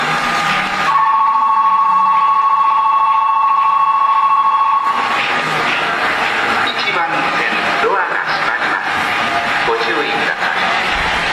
ベル 私鉄で多用されています。